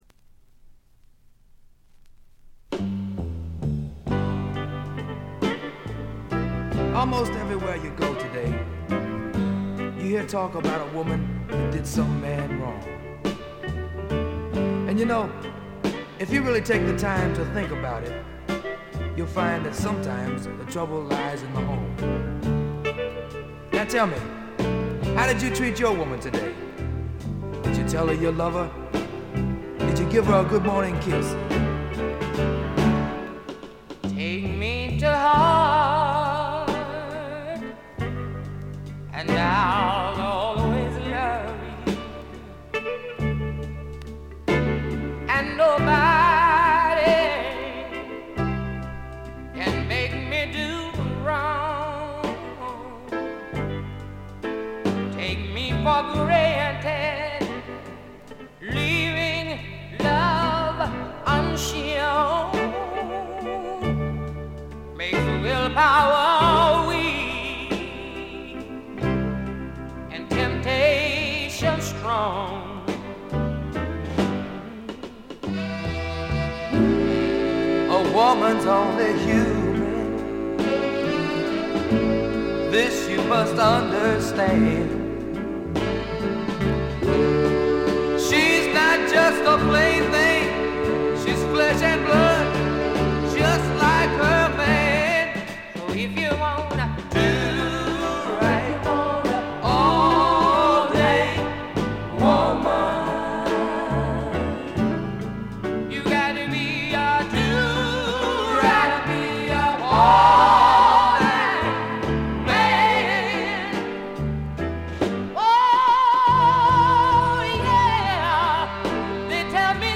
ほとんどノイズ感無し。
試聴曲は現品からの取り込み音源です。